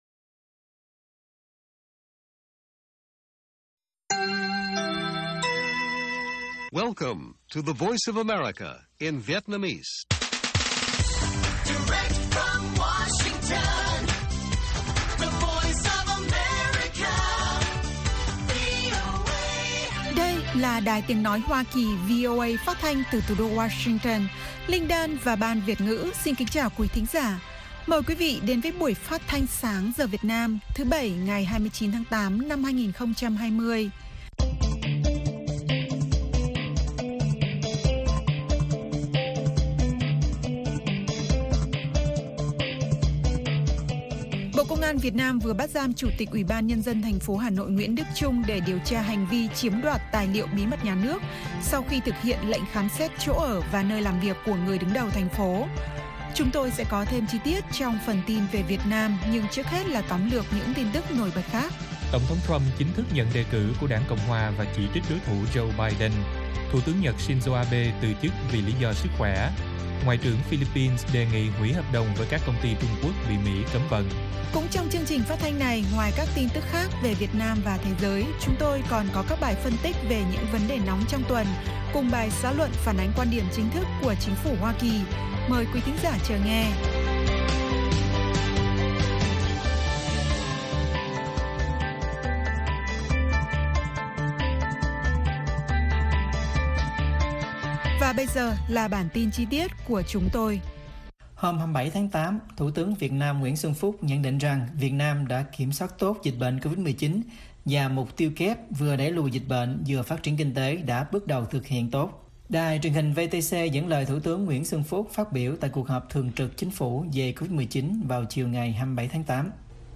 Bản tin VOA ngày 29/8/2020